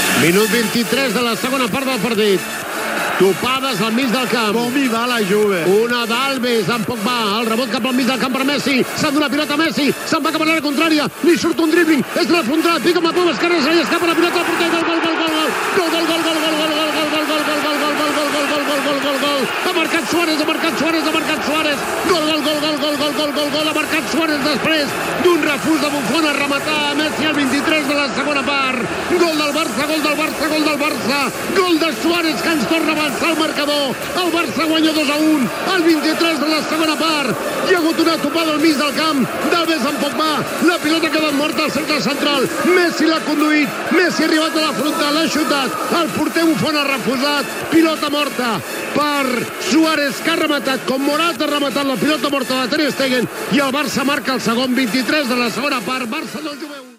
Transmissió de la final de la Copa d'Europa de futbol masculí, des de l'Olympiastadion de Berlín, entre el Futbol Club Barcelona i la Juventus de Milàs.
Narració del gol de Luis Suárez (2-1)
Esportiu